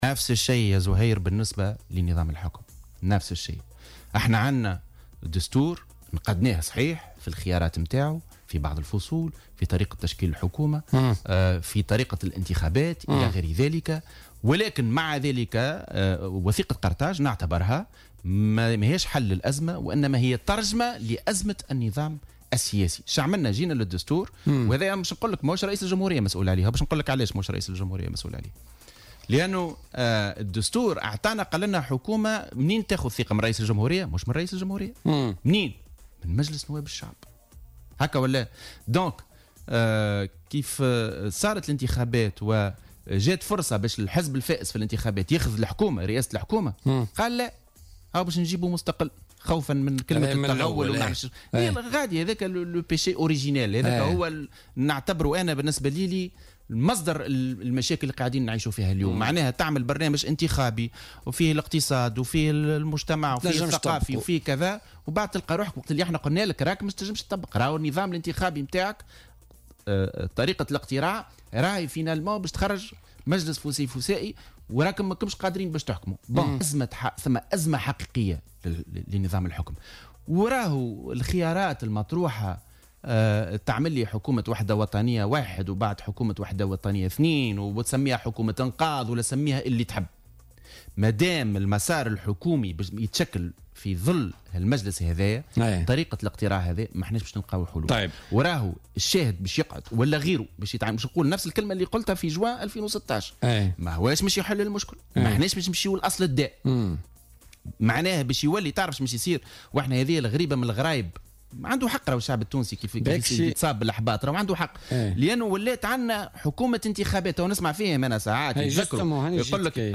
وأضاف في مداخلة له اليوم في برنامج "بوليتيكا" أن النظام الانتخابي قاد إلى "أزمة حكم" نظرا لأنه لم يفرز أغلبية مطلقة وفرض تشكيل حكومة ائتلافية ليس لها برامج واضحة. وأوضح أنه لابد من اتخاذ قرارات جريئة لتغيير طريقة الاقتراع وحل البرلمان وإجراء انتخابات سابقة لأوانها، وفق قوله.